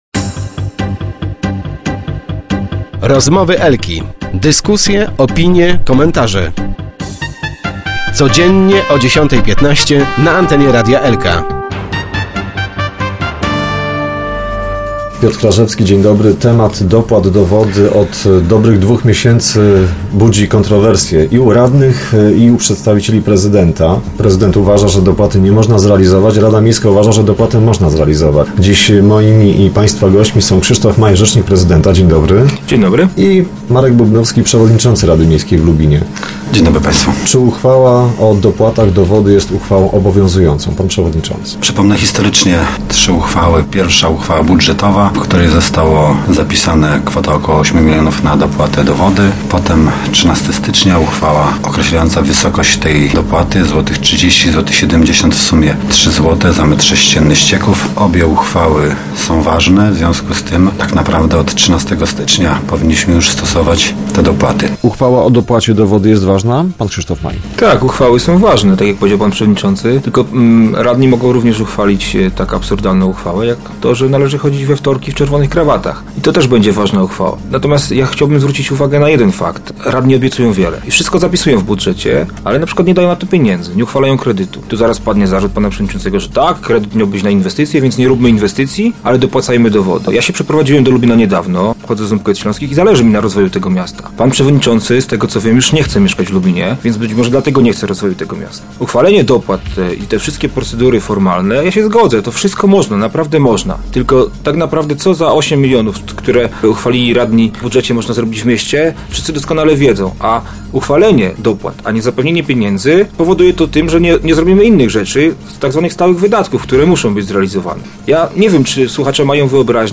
Argumenty w tej sprawie w naszym lubi�skim studio przedstawiali Marek Bubnowski, przewodnicz�cy rady miejskiej